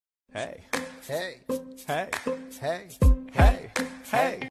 Meaww sound effects free download